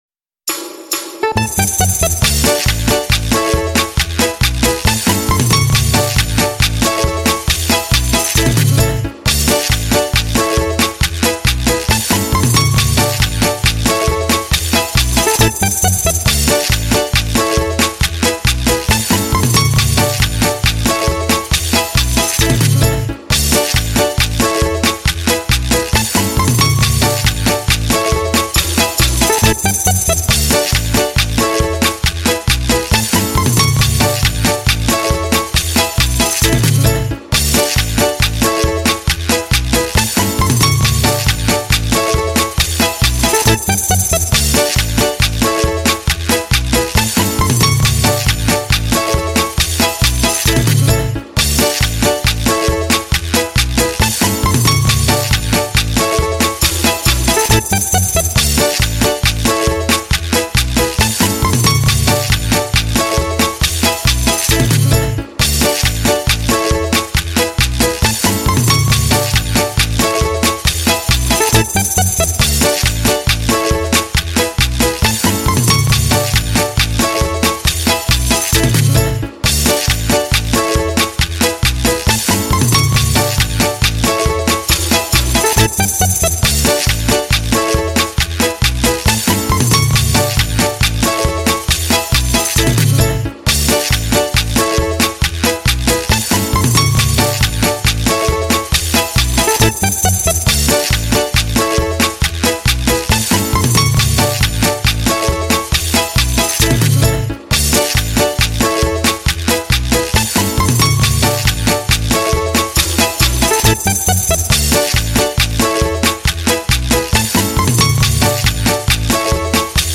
Музыка для частушек минус